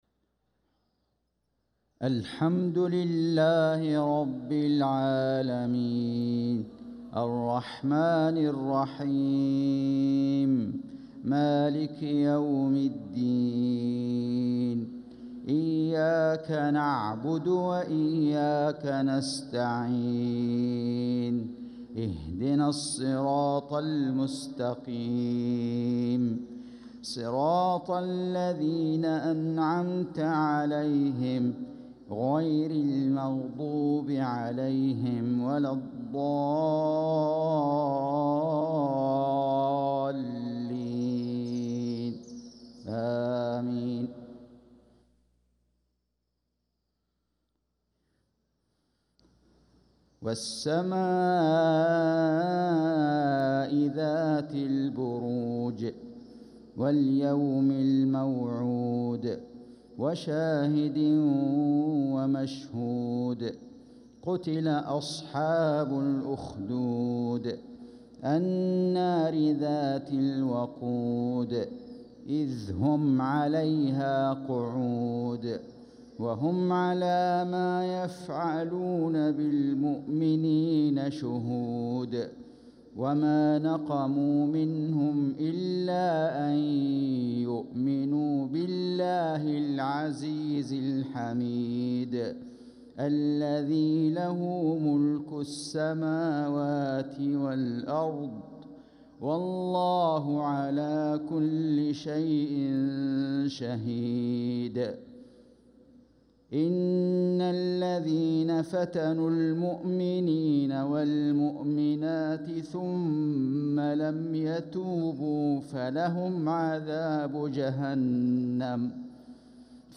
صلاة المغرب للقارئ فيصل غزاوي 13 ربيع الآخر 1446 هـ
تِلَاوَات الْحَرَمَيْن .